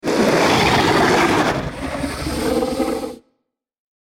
GURGLE_GROWL-Audio.mp3